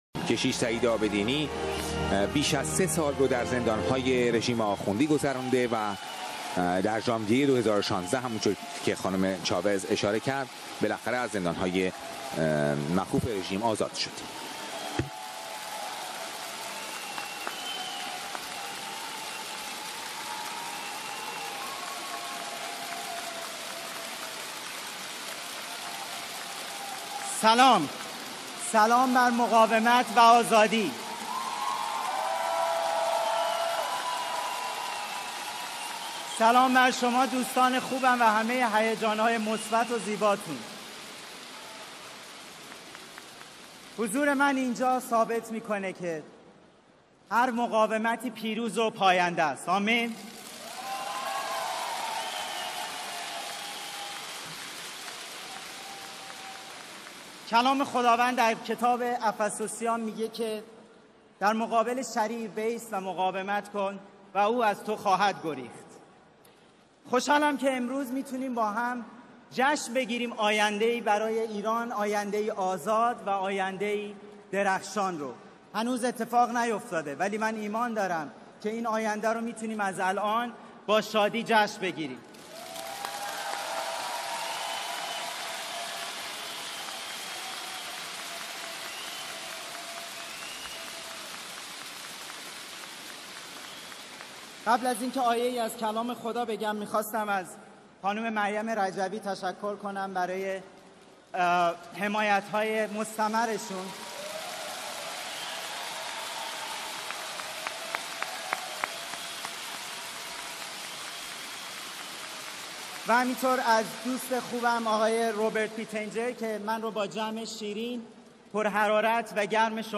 فیلم – سخنرانی کشیش سعید عابدینی در بزرگترین گردهمایی مقاومت ایران در پاریس